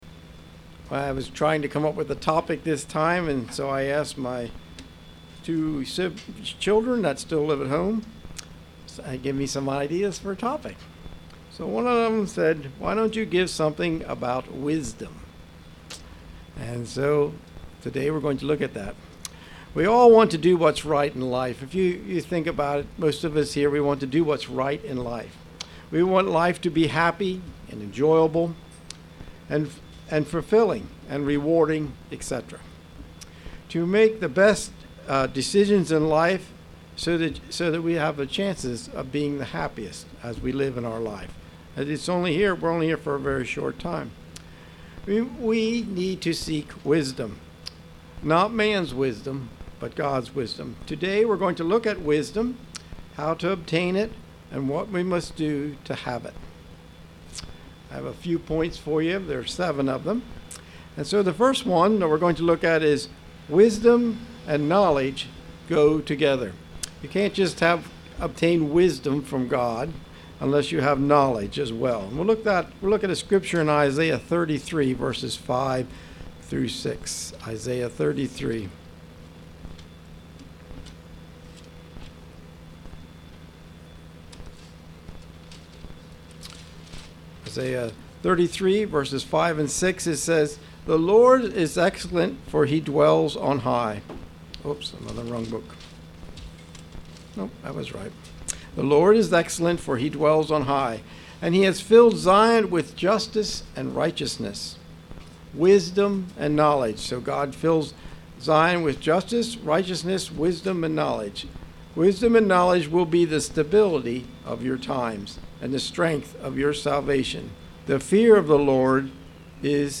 Sermons
Given in York, PA Lewistown, PA